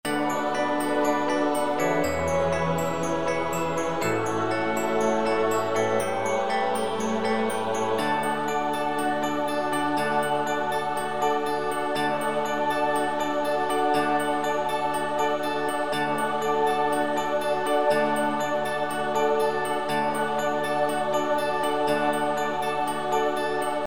発車メロディー一覧
4番線 特急ホーム 南緋嵜･漆黒のめたん･臘花中央方面